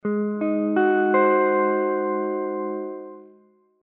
ab_dominant7.mp3